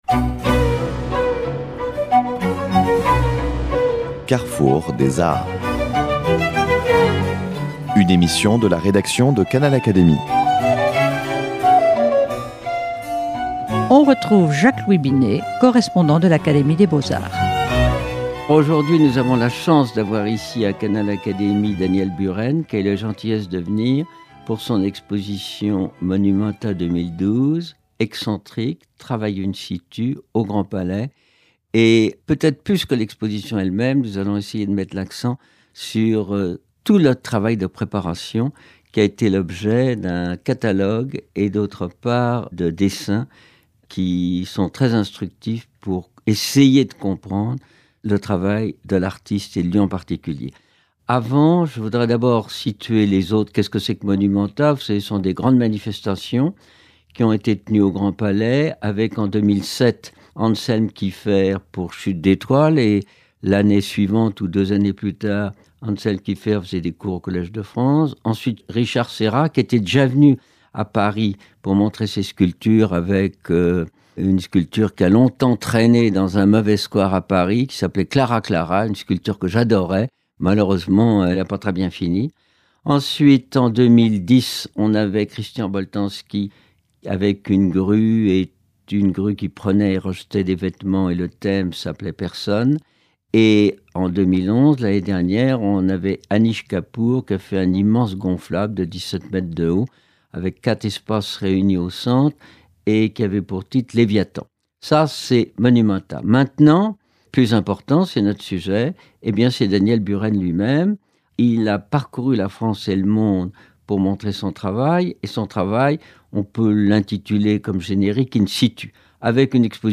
L’artiste contemporain revient dans cet entretien sur la genèse de son œuvre, ainsi que sa préparation, son installation et son sens, une belle occasion de prolonger un peu cette expérience artistique étonnante.